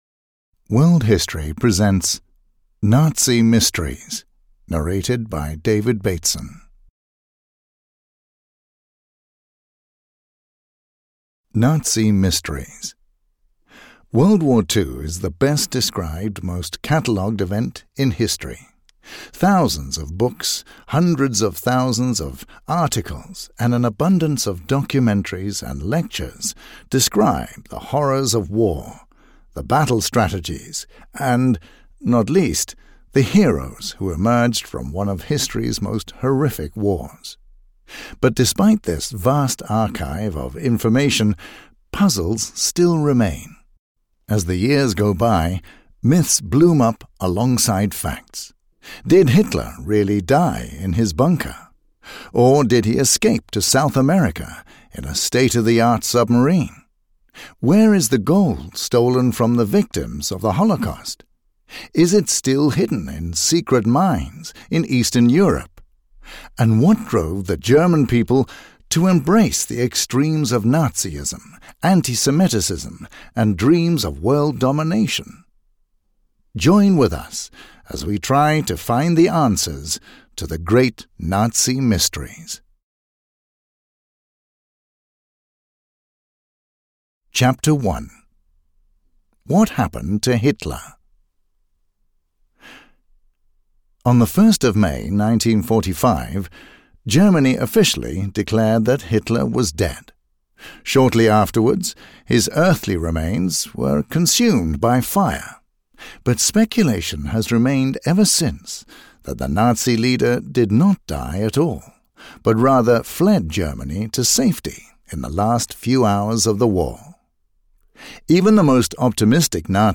Nazi Mysteries (EN) audiokniha
Ukázka z knihy